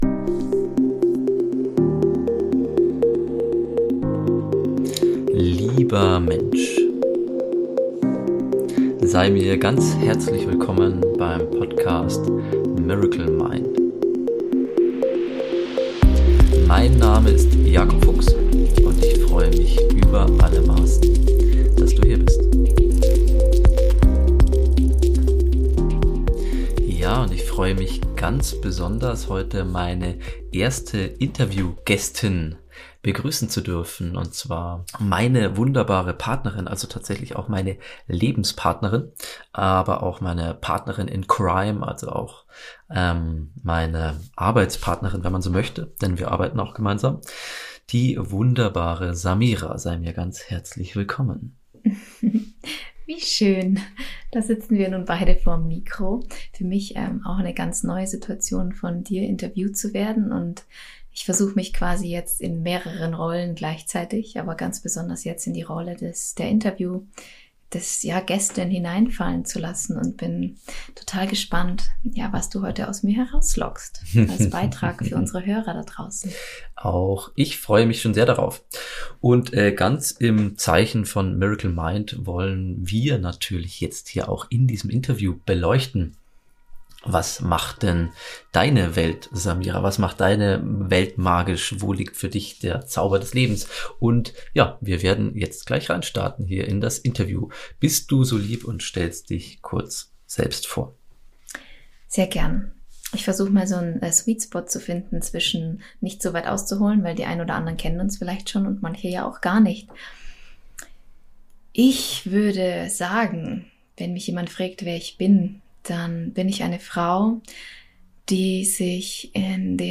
6. Interview